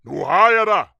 Voices / Gardener
Gotcha3.wav